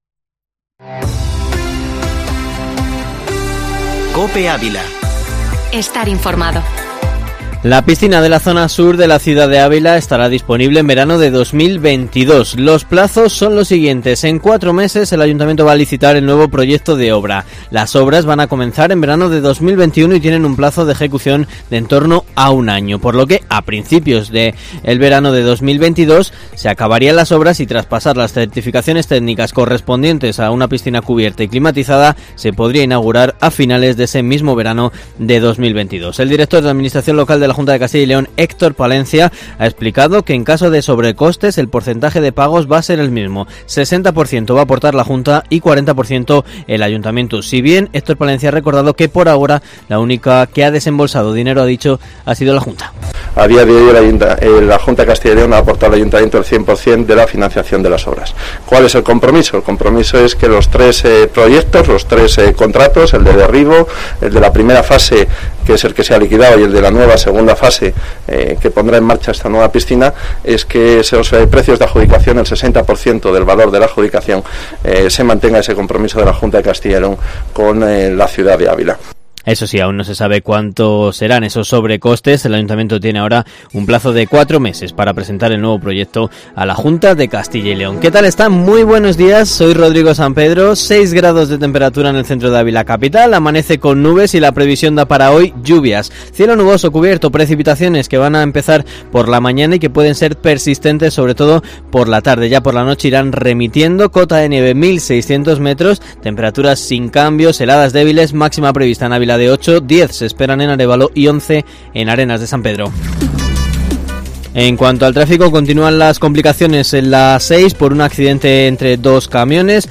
Informativo matinal Herrera en COPE Ávila 16/12/2020